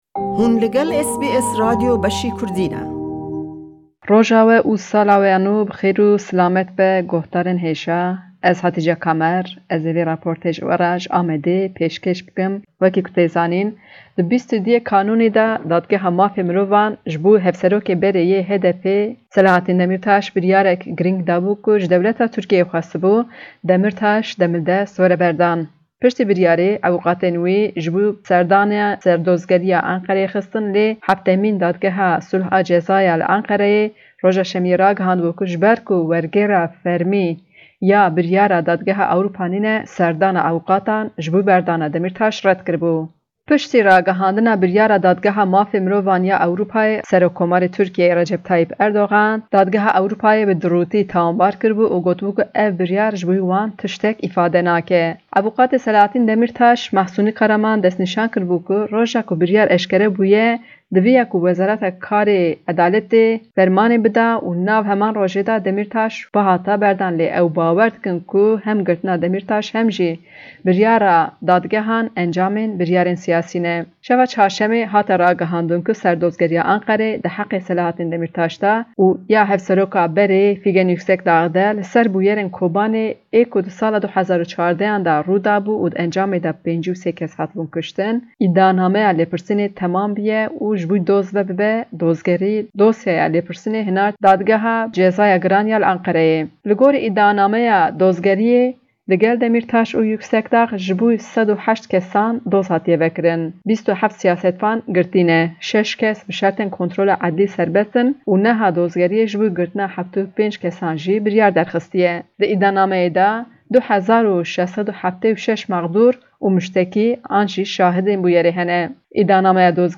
report from Diyarbakir